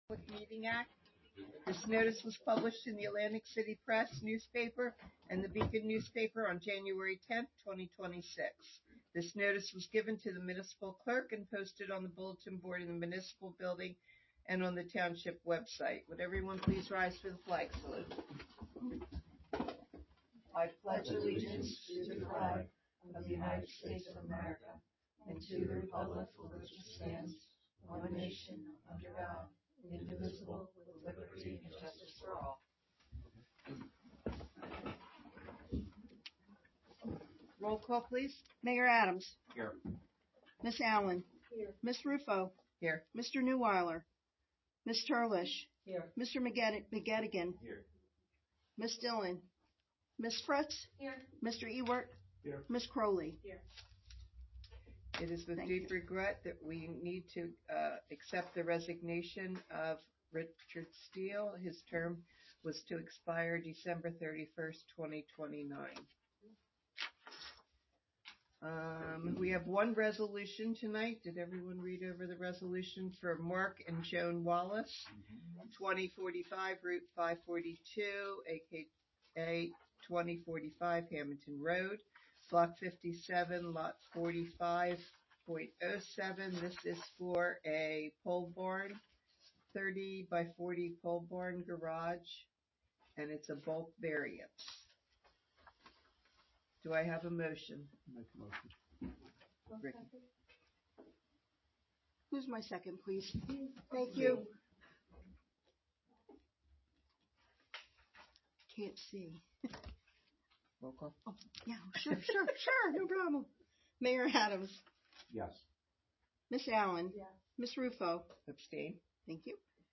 Recording of April 15, 2026 Meeting